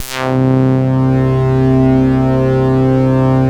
BAND PASS OB.wav